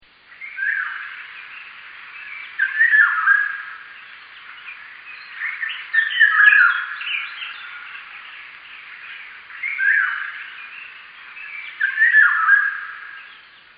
Песня иволги
ivolga.mp3